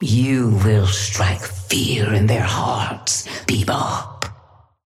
Sapphire Flame voice line - You will strike fear in their hearts, Bebop.
Patron_female_ally_bebop_start_03.mp3